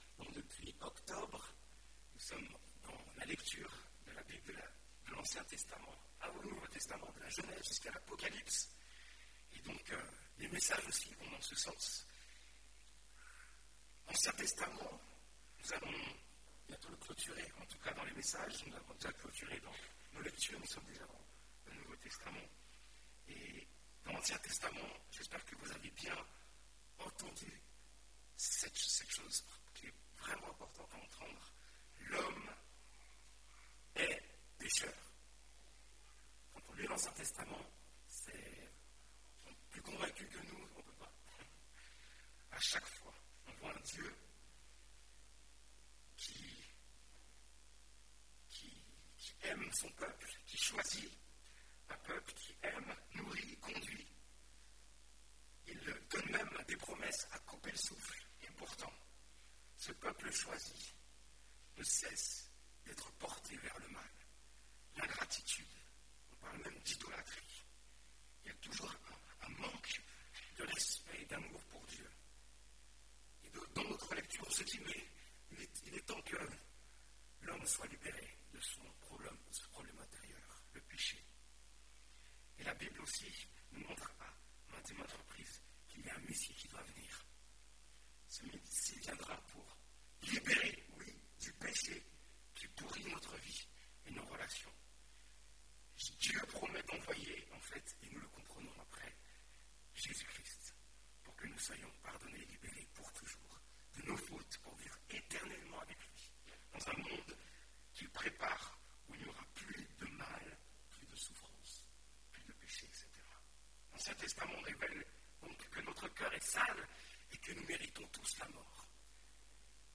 Comment réagir face au péché qui mène à la ruine – Église Protestante Évangélique d'Ozoir